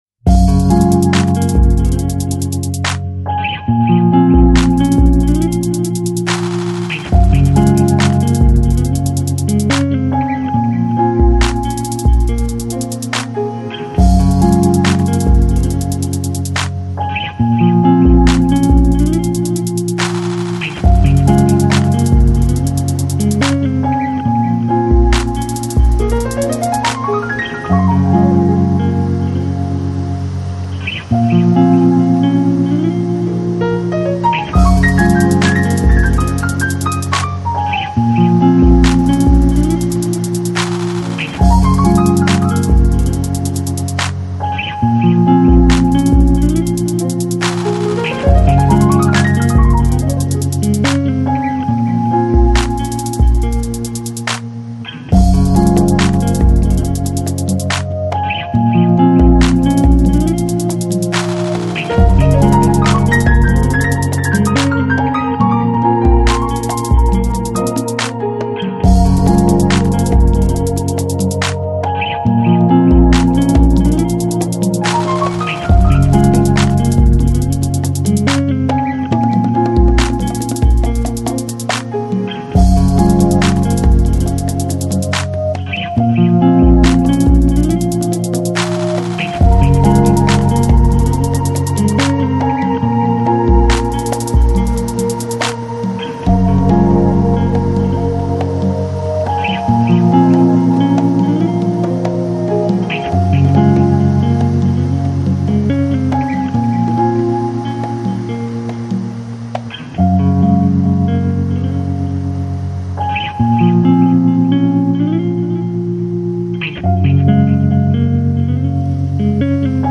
Balearic, Downtempo Издание